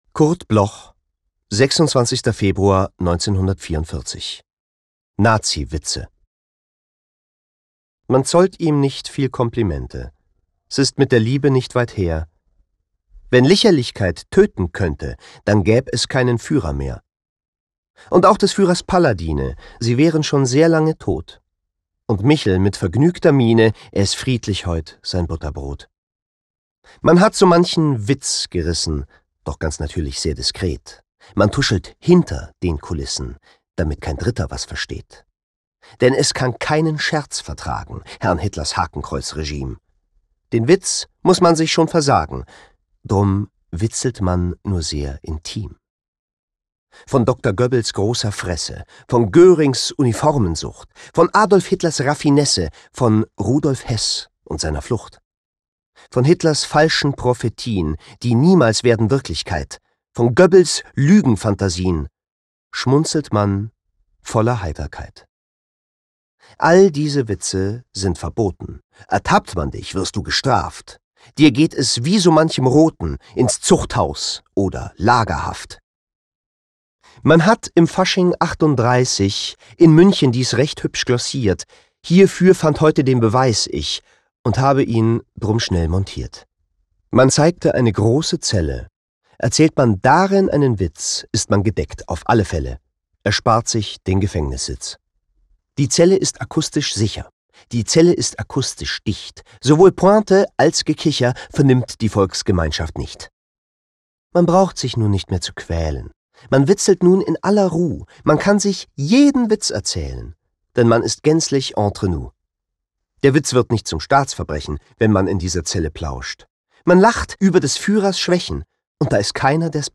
recorded at Kristen & Schmidt, Wiesbaden